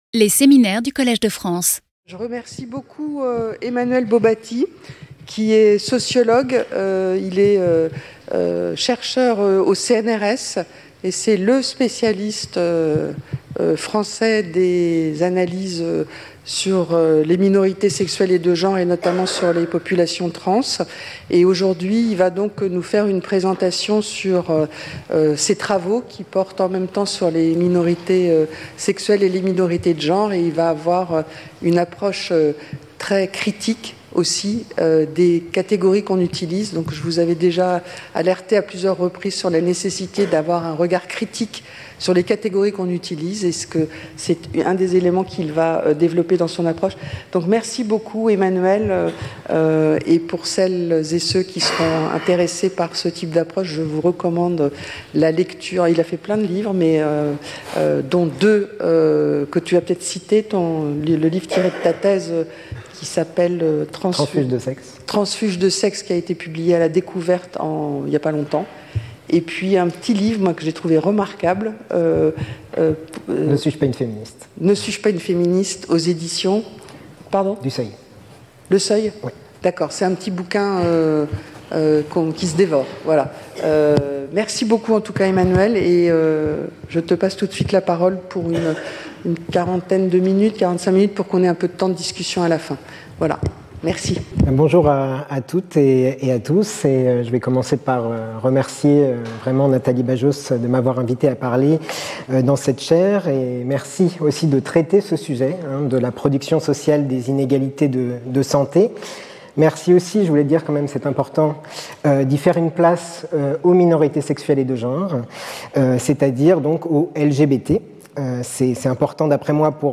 Cette présentation propose de rendre compte de cette pluralité, ainsi que des enjeux de santé associés. Longtemps cantonnées à l’étude de l’infection à VIH chez les hommes gays, les recherches sur la santé sexuelle des LGBT se sont peu à peu étendues aux autres lettres de l’acronyme.